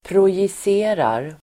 Uttal: [projis'e:rar]